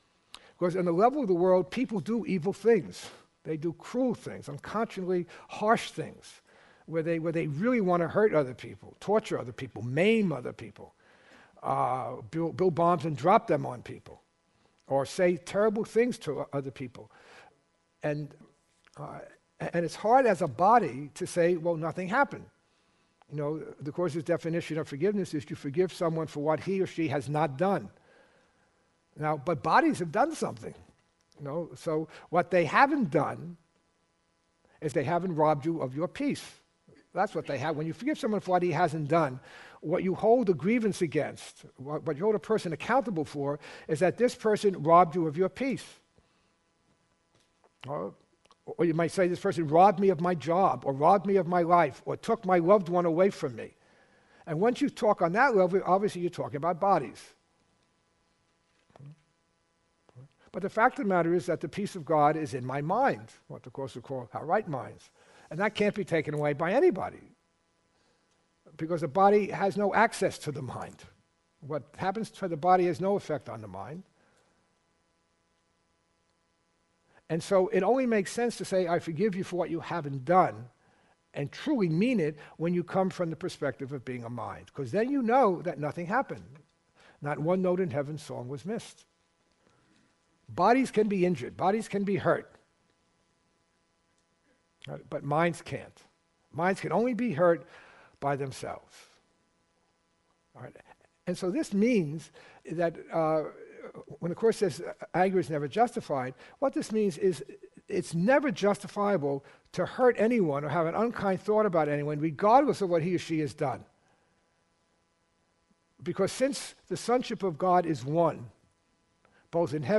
This three-day Academy looks at the difference between the world’s view of justice and Heaven’s justice. ln the world’s justice, there is a winner and there is a loser while the justice of Heaven can be summarized by the phrase that no one can lose for anyone to gain.